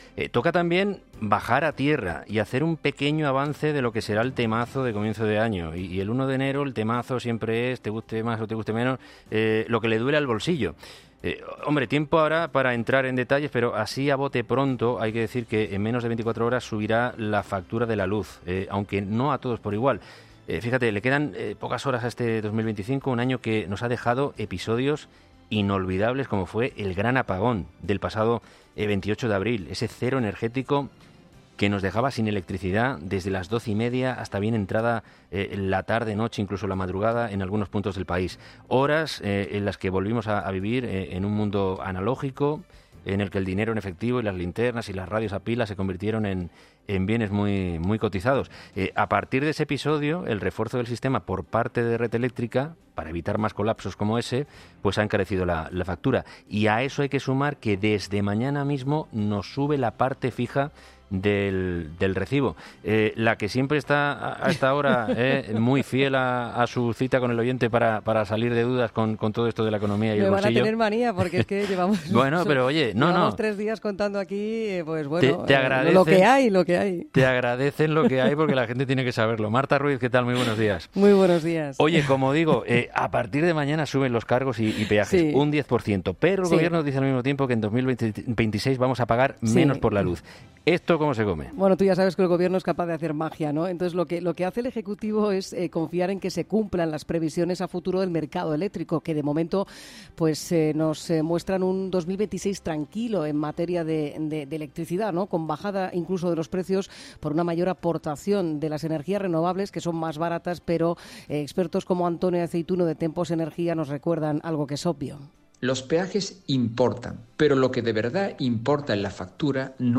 experta económica, explica cuánto pagará cada familia española este 2026 por la luz